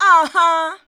AHA 1.wav